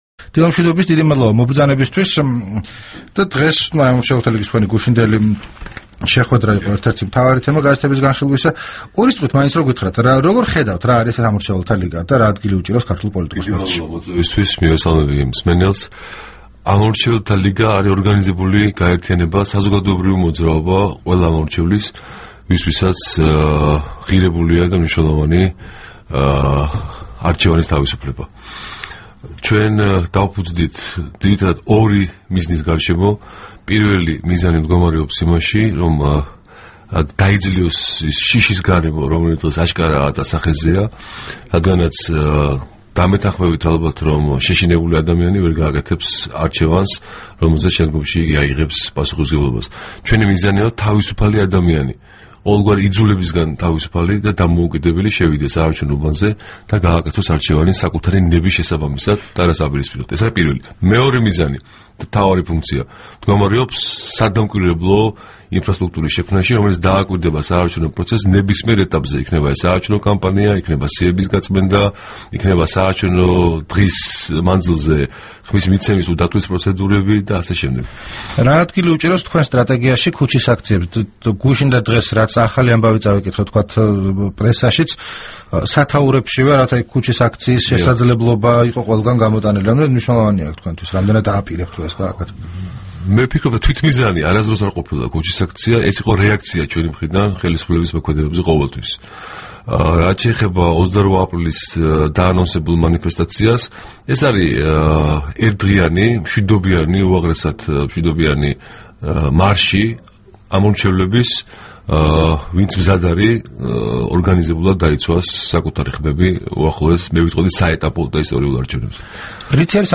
რადიო თავისუფლების თბილისის სტუდიის სტუმარი იყო